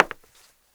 Index of /90_sSampleCDs/AKAI S6000 CD-ROM - Volume 6/Human/FOOTSTEPS_1
HARDWOOD 4.WAV